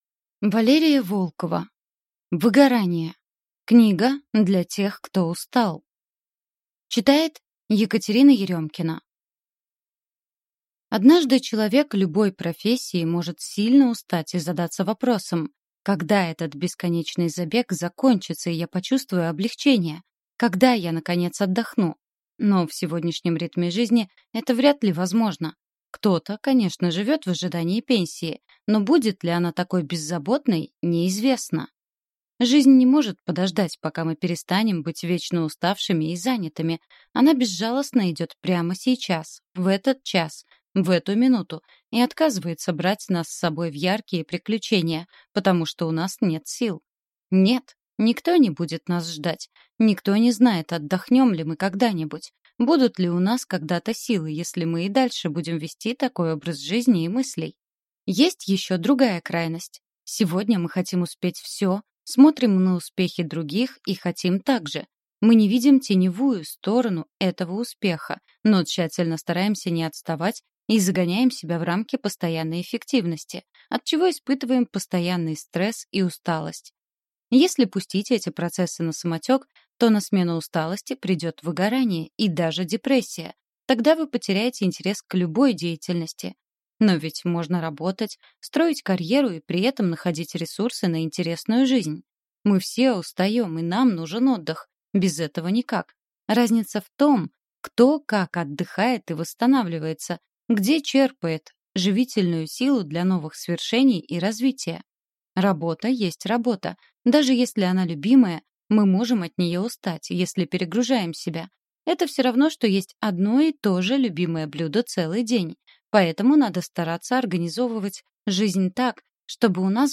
Аудиокнига Выгорание. Книга для тех, кто устал | Библиотека аудиокниг